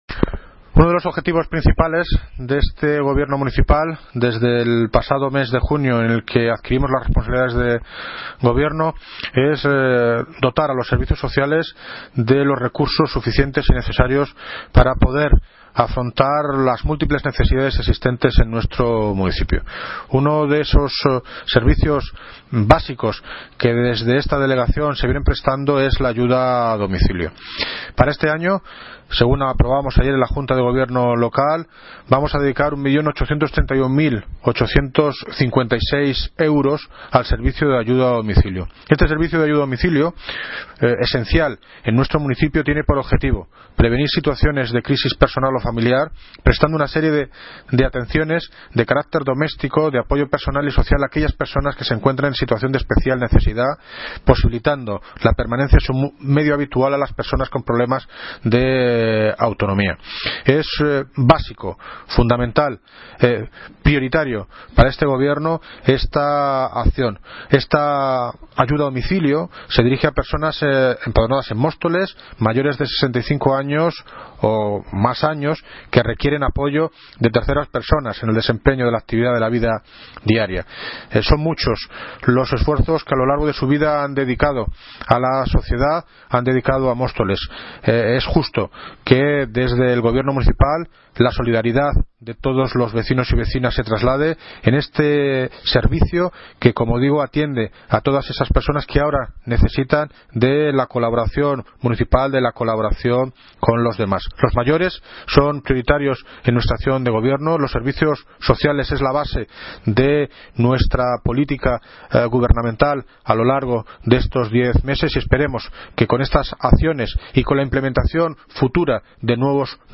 Audio - David Lucas (Alcalde de Móstoles) Sobre AYUDA A DOMICILIO